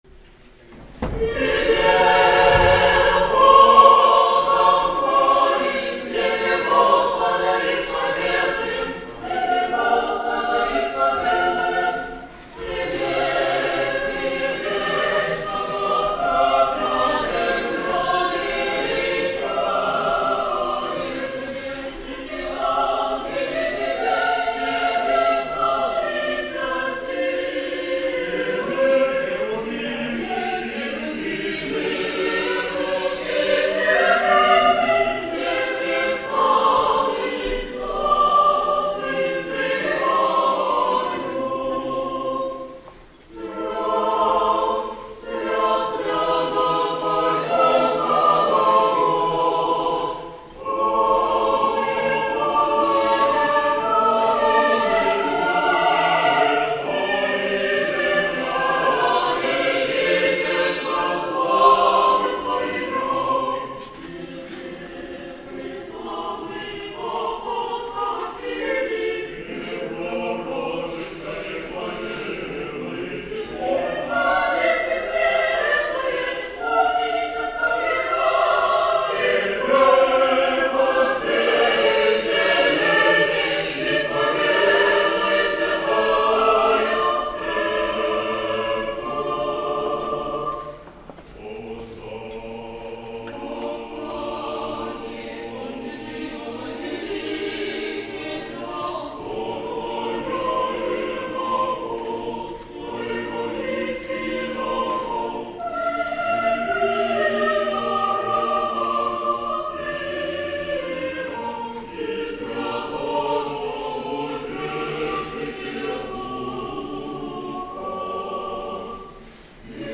Владыка Игнатий преподал архипастырское благословение прихожанам Покровского храма, а хор исполнил древний церковный гимн "Тебе, Бога хвалим".